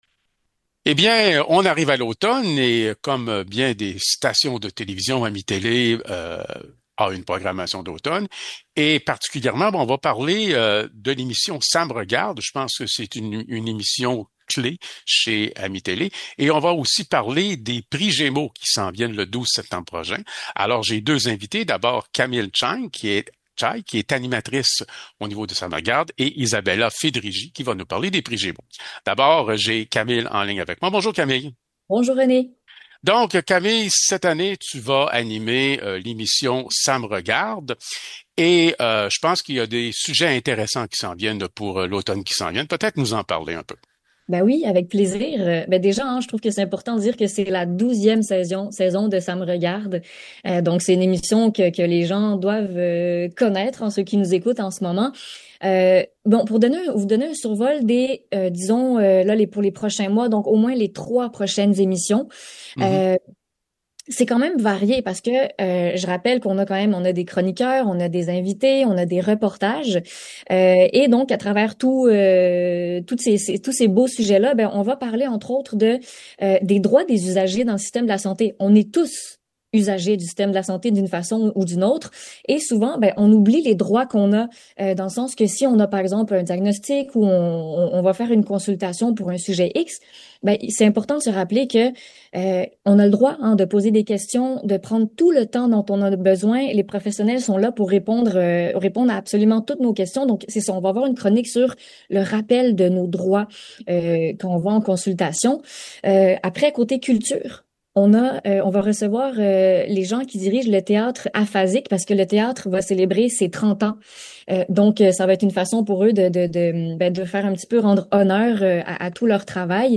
Nous continuons nos entrevues avec les artisans d’AMI-Télé. Ce mois-ci, deux invitées.